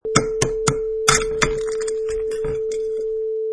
Royalty free sound: Crack Open Egg with Knife 3
Cracking open boiled egg with knife
Product Info: 48k 24bit Stereo
Try preview above (pink tone added for copyright).